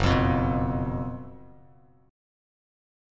F#M7sus4 chord